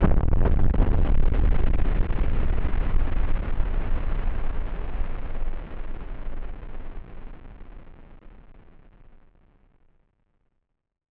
BF_DrumBombB-02.wav